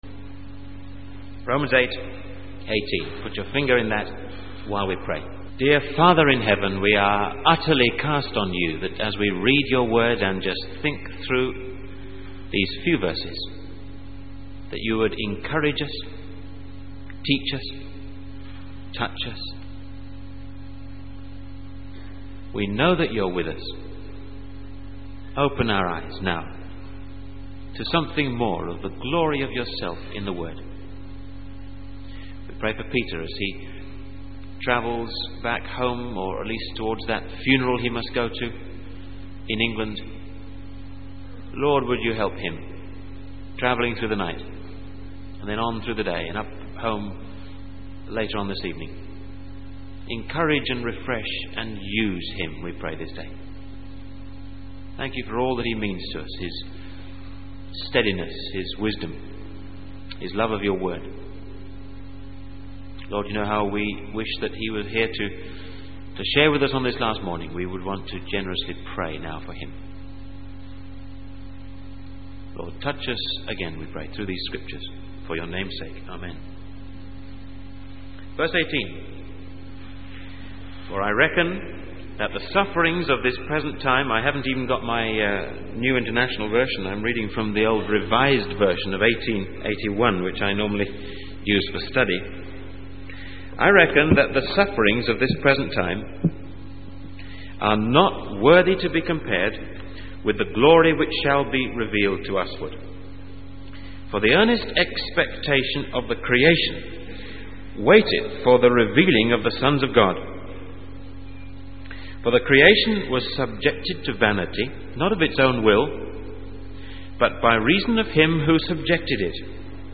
In this sermon, the speaker encourages the audience to reflect on God's ultimate objectives for their lives.